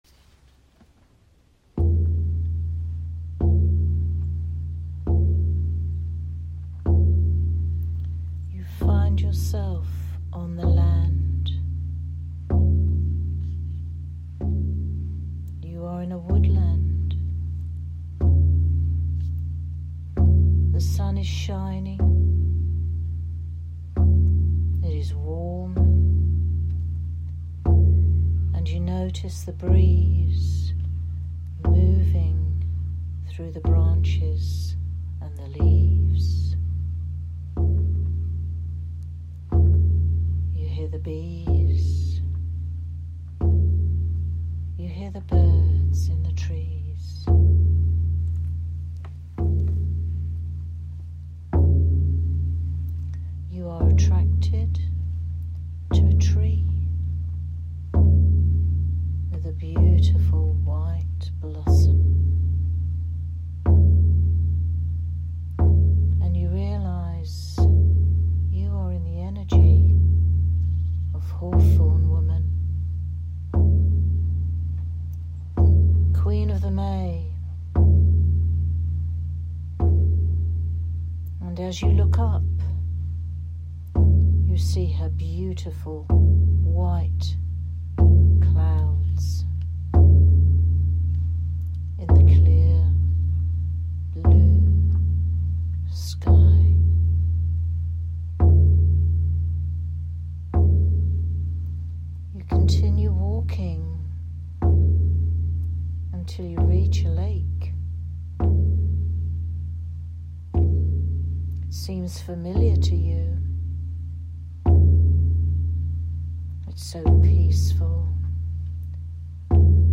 I haven’t put this on a video, because I actually meditate at the same time as I am recording it! So basically, I am sharing with you my meditation.